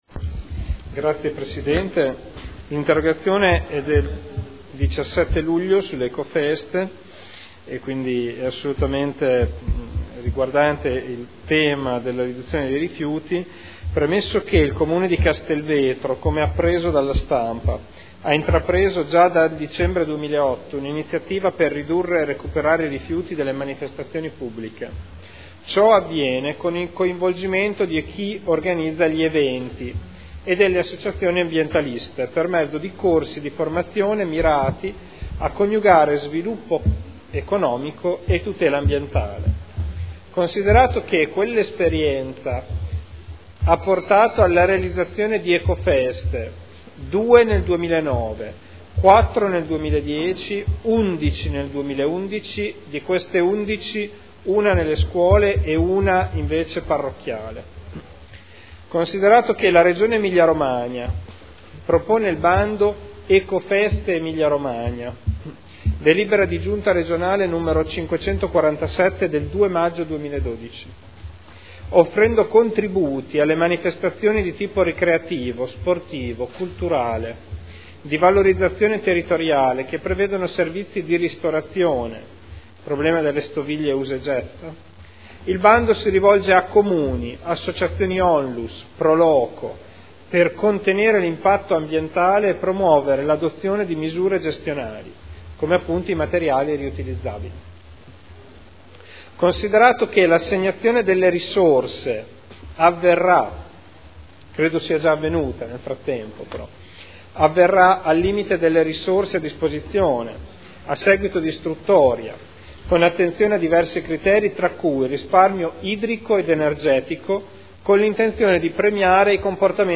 Seduta del 05/11/2012. Interrogazione del consigliere Ricci (Sinistra per Modena) avente per oggetto: “Ecofeste”